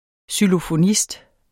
Udtale [ sylofoˈnisd ]